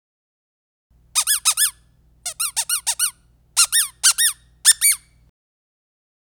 Squeaker Accordian Double-Voice - Small (12 Pack) - Trick
Double-voice squeakers make a sound both when they are pressed and when they are released.